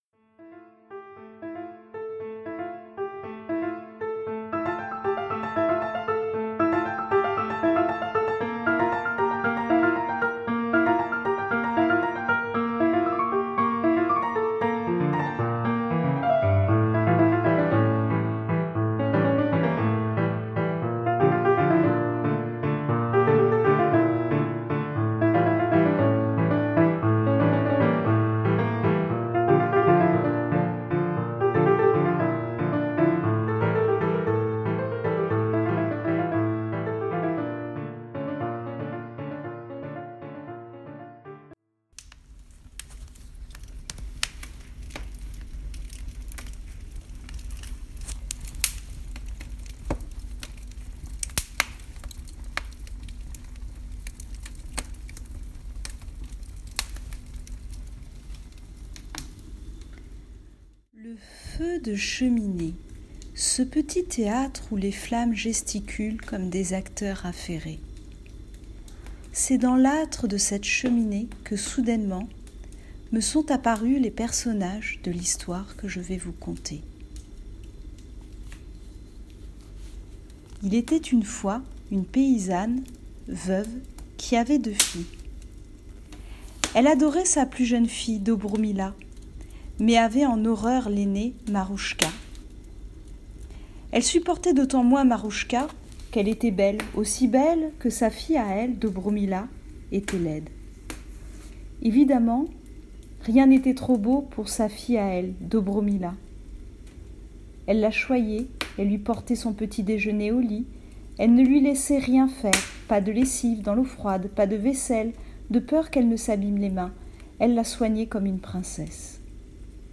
Vous trouverez ci-dessous un conte paysan, à la morale écologique. Après l'avoir écouté, vous pouvez proposer à votre enfant de vous dessiner le jardin de Marouchka.